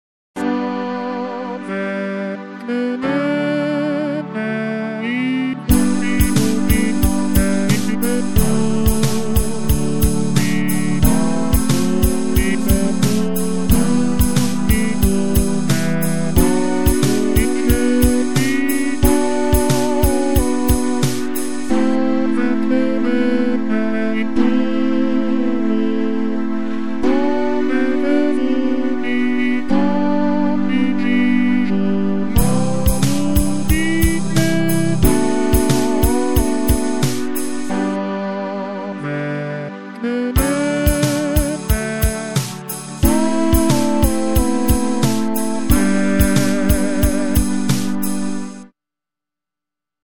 Mp3 – Demo digitale